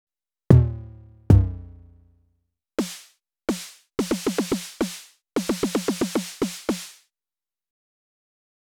Quick attempt at 909 tom and snare sounds…